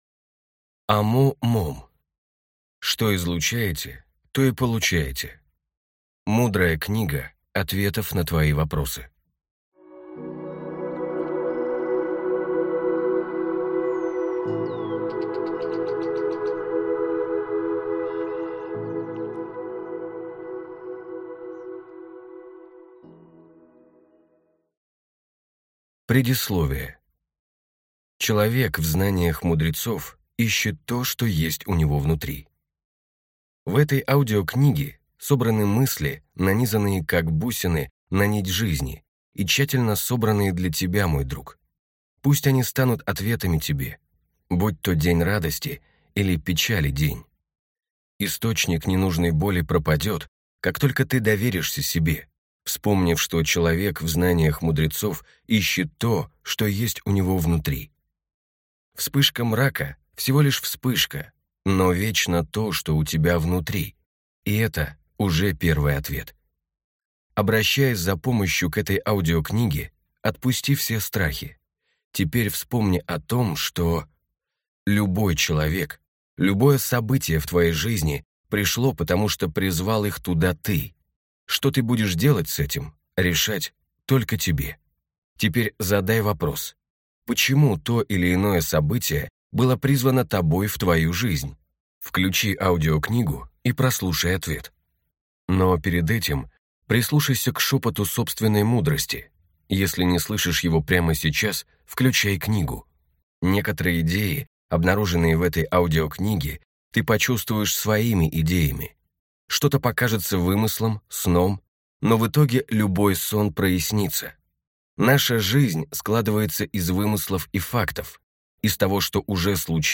Аудиокнига Что излучаете, то и получаете. Мудрая книга ответов на твои вопросы | Библиотека аудиокниг